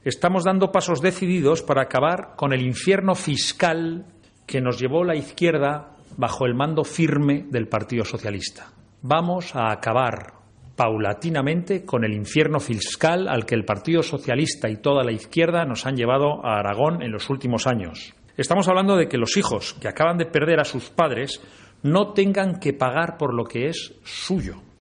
El portavoz del PP Fernando Ledesma, valora el anuncio de Azcón sobre el Impuesto de Sucesiones.